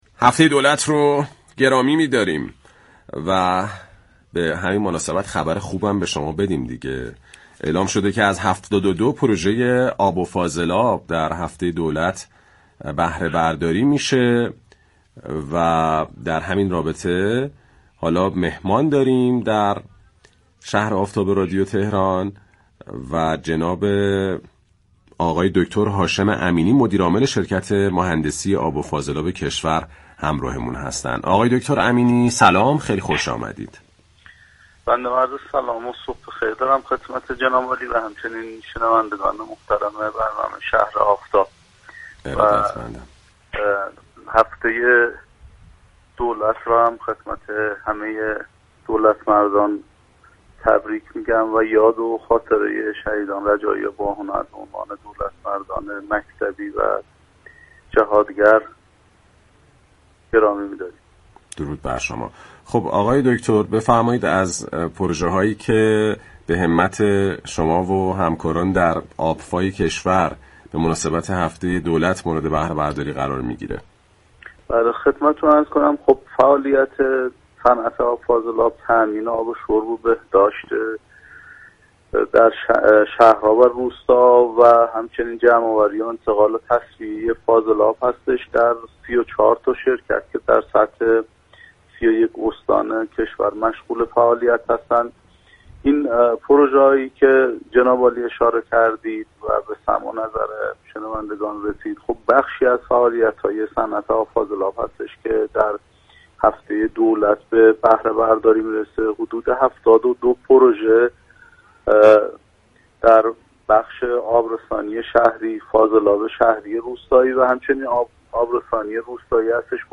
دریافت فایل به گزارش پایگاه اطلاع رسانی رادیو تهران، هاشم امینی مدیرعامل شركت مهندسی آب و فاضلاب كشور در گفت و گو با «شهر آفتاب» اظهار داشت: وظیفه صنعت فاضلاب تامین آب شرب، جمع آوری و تصفیه آب و فاضلاب شهرها و روستا‌ها كشور است.